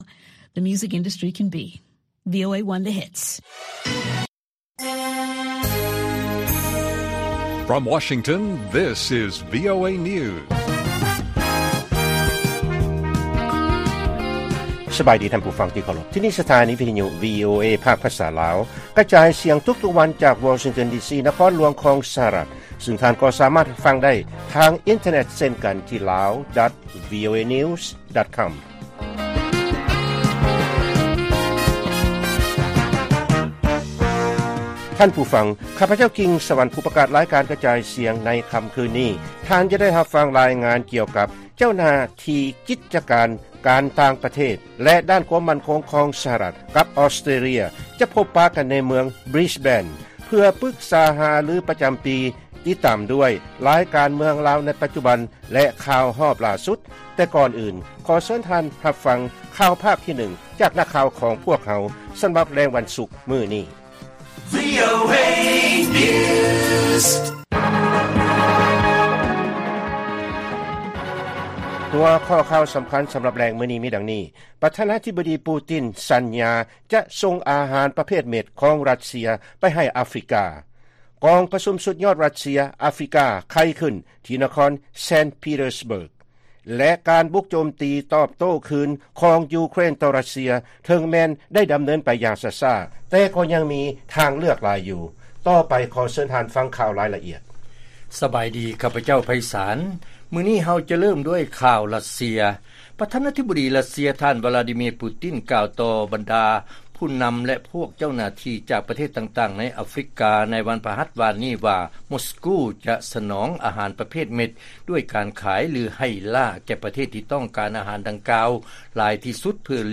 ວີໂອເອພາກພາສາລາວ ກະຈາຍສຽງທຸກໆວັນ, ຫົວຂໍ້ຂ່າວສໍາຄັນໃນມື້ນີ້ມີ: 1.